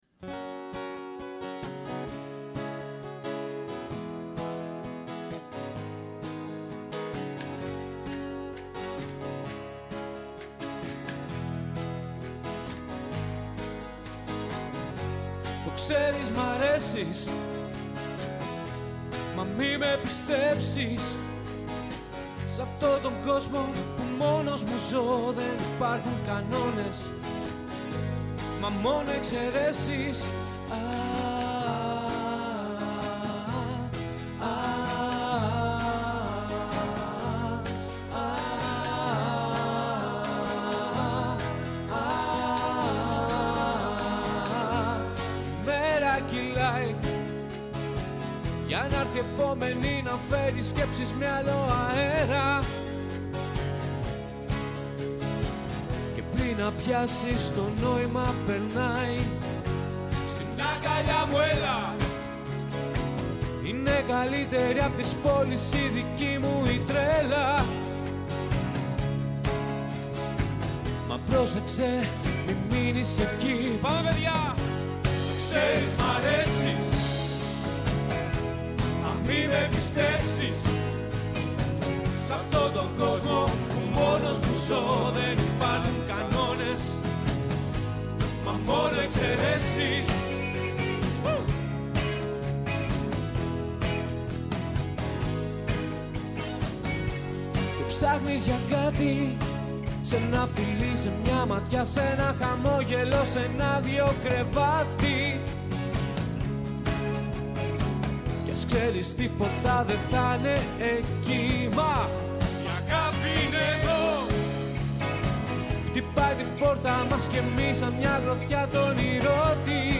Ενστάσεις, αναλύσεις, παρατηρήσεις, αποκαλύψεις, ευχές και κατάρες, τα πάντα γίνονται δεκτά. Όλα όσα έχουμε να σας πούμε στο Πρώτο Πρόγραμμα της Ελληνικής Ραδιοφωνίας, Δευτέρα έως και Πέμπτη, 1 με 2 το μεσημέρι.